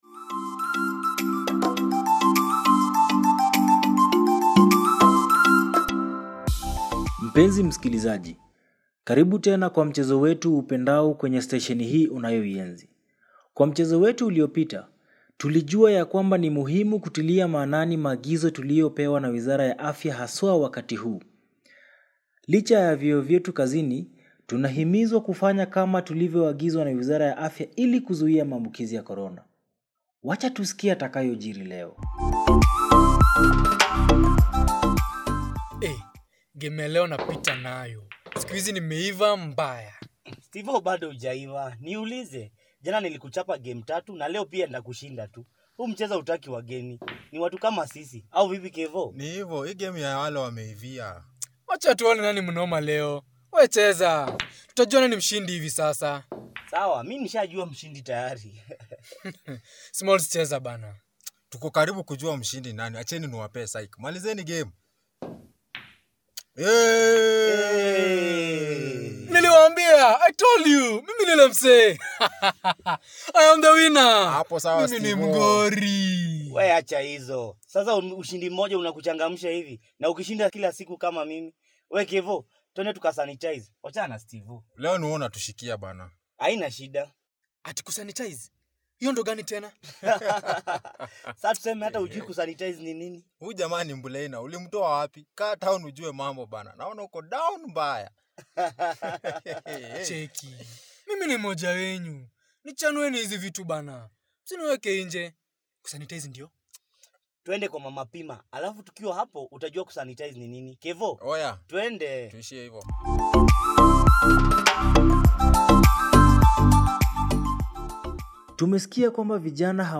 Alcohol consumption cannot prevent you from contracting coronavirus, why should our youth refrain from believing in this myth? Listen to this hilarious Swahili radio drama by Linda Arts theatre Group.
Episode-6.-Radio-Drama.mp3